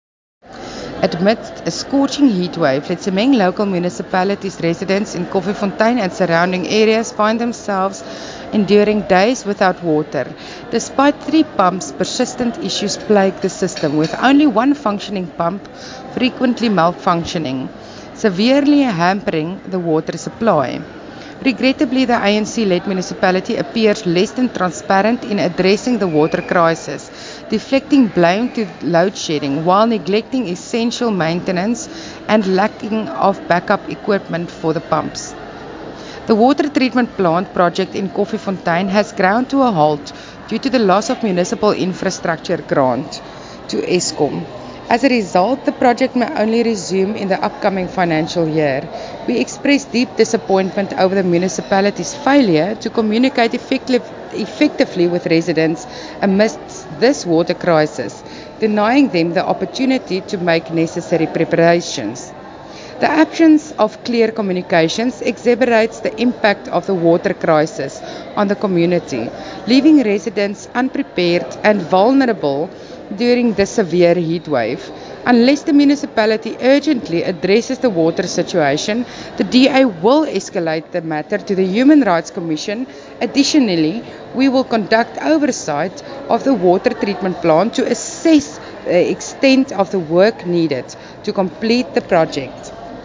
English and Afrikaans soundbites by Cllr Mariska Potgieter and